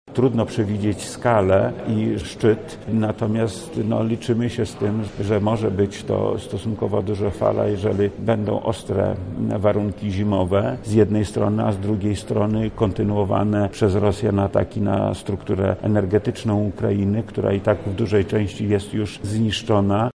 • mówi wojewoda lubelski Lech Sprawka.